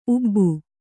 ♪ ubbu